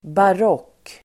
Uttal: [bar'åk:]